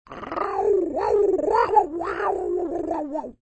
Descarga de Sonidos mp3 Gratis: criatura 19.